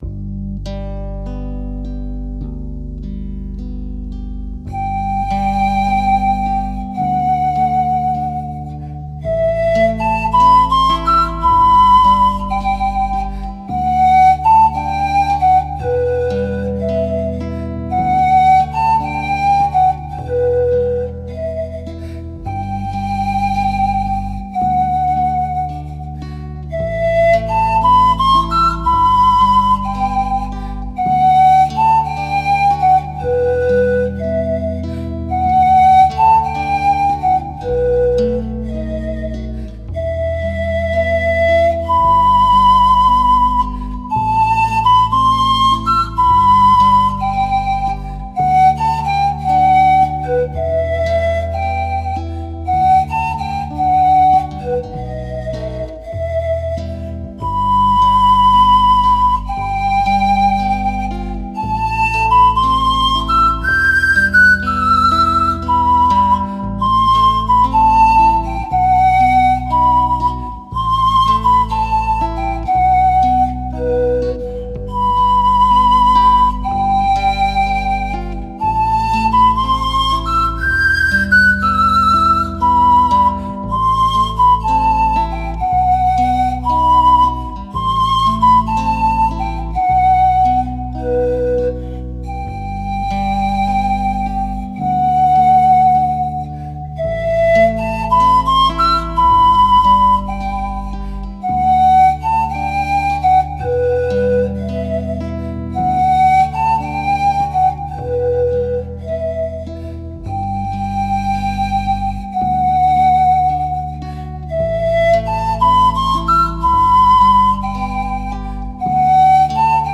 Нежные, убаюкивающие колыбельные и советы о том, как устроить сон малышки, как подготовить его ко сну, какой режим соблюдать и чем лучше заниматься с ним до и после сна. 1.